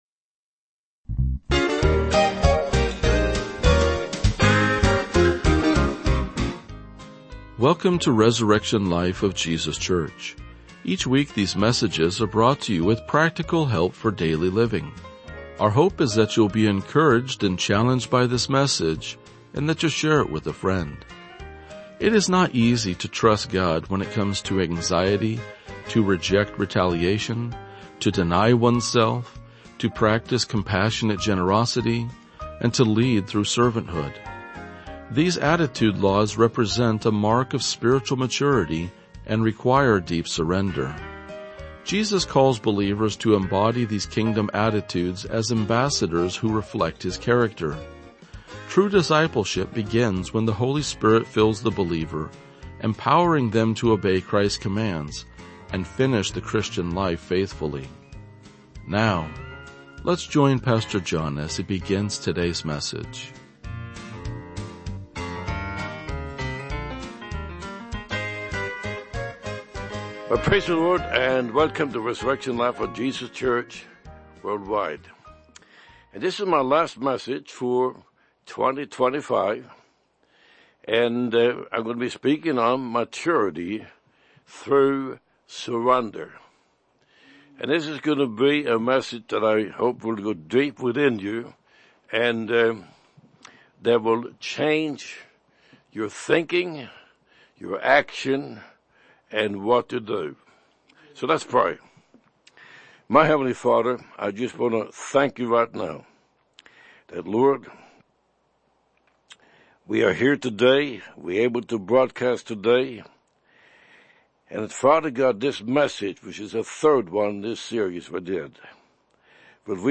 RLJ-2047-Sermon.mp3